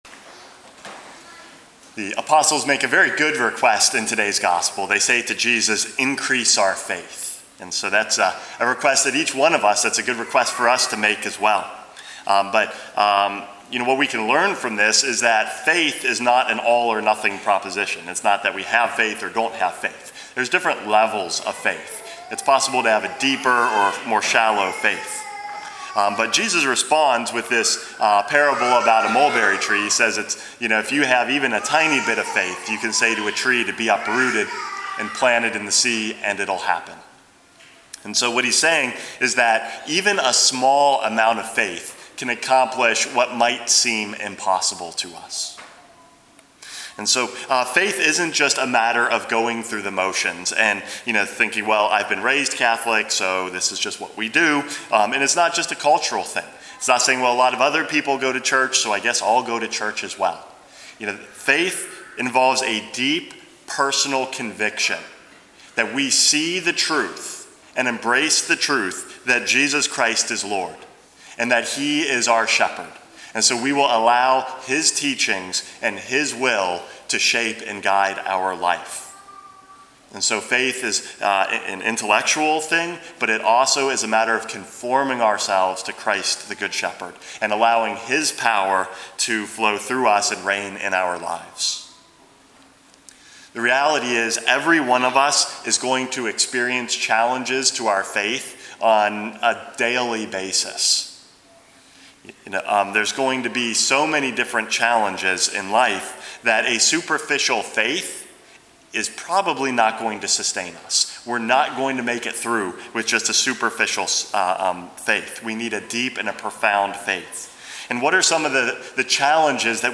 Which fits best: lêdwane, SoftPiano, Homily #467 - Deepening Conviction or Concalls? Homily #467 - Deepening Conviction